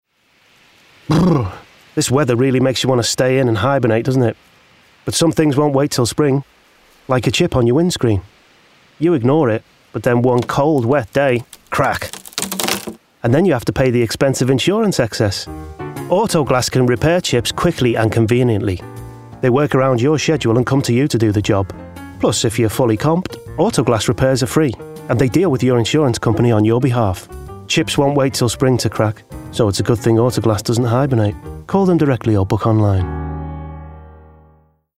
40/50's Northern/Liverpool,
Warm/Natural/Reassuring